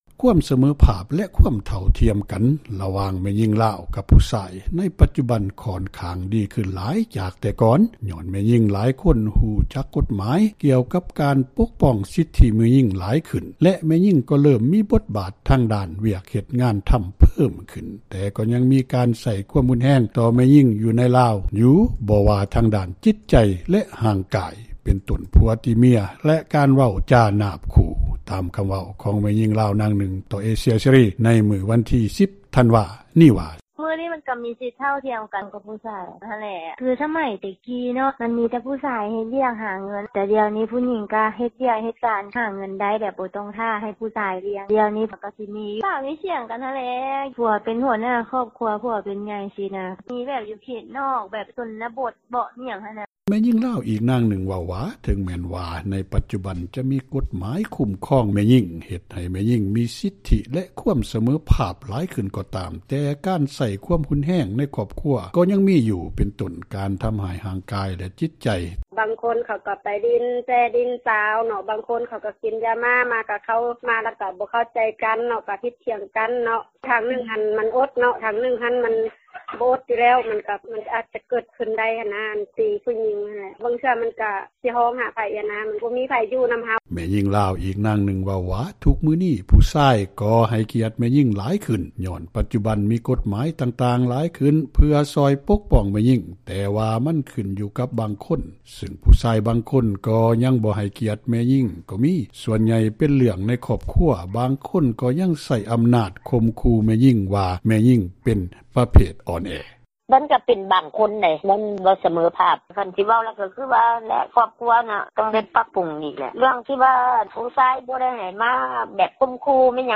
ຕາມຄຳເວົ້າ ຂອງແມ່ຍິງລາວ ນາງນື່ງ ຕໍ່ເອເຊັຽເສຣີ ໃນມື້ວັນທີ 10 ທັນວາ ນີ້ວ່າ: